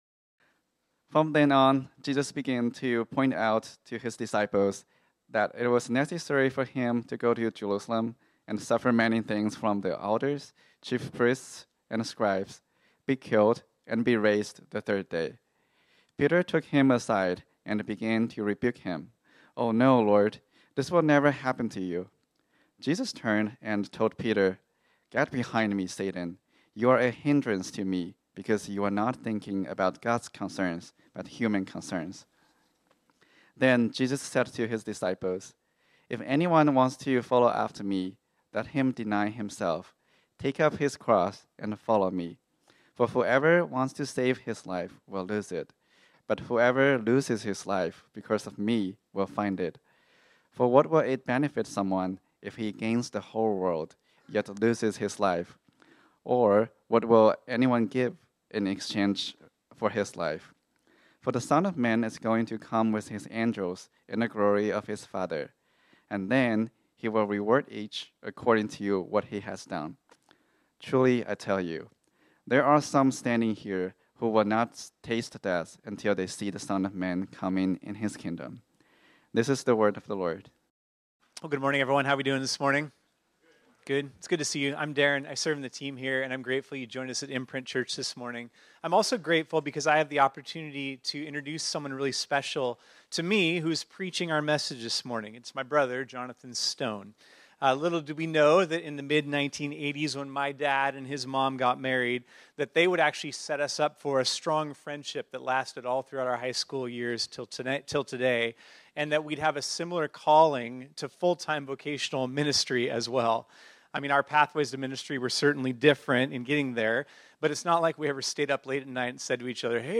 This sermon was originally preached on Sunday, July 28, 2024.